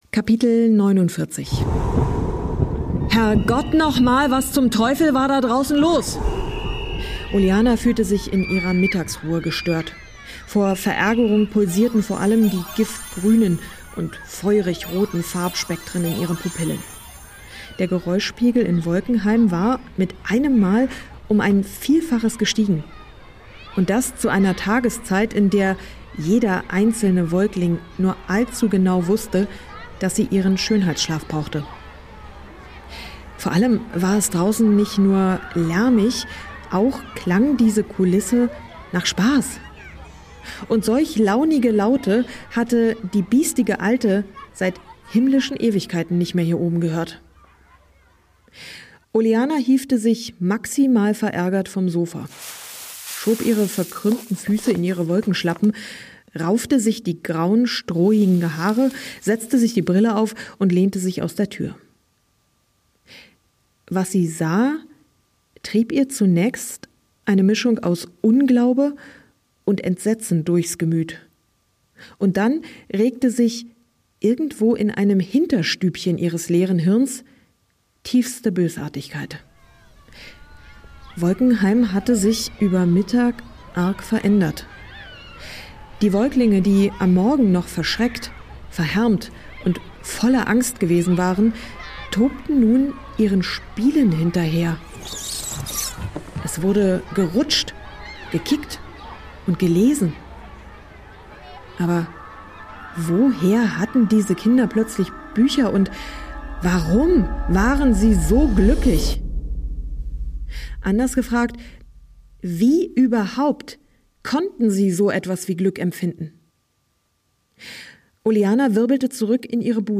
Ein atmosphärisches Hörerlebnis für alle, die sich gern davon und in die Wolken träumen. Eine Geschichte über all die Gefühle, die unser Leben erst bunt machen.